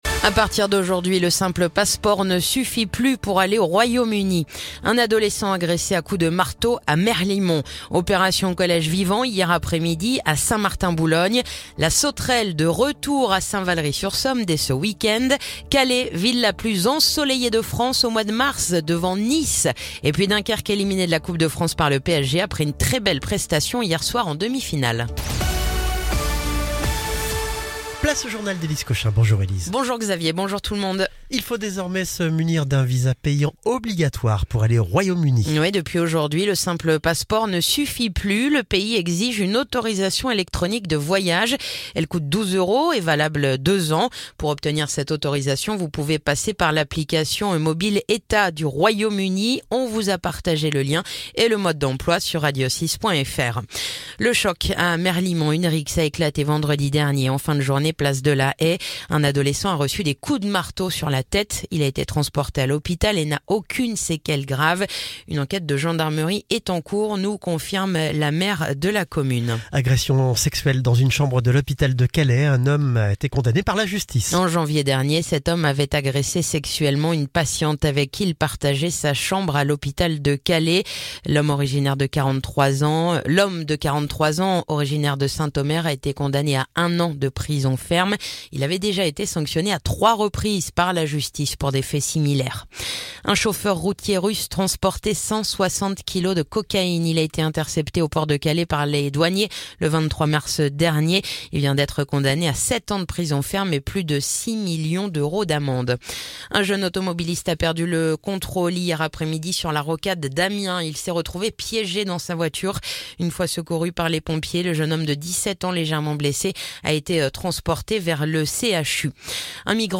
Le journal du mercredi 2 avril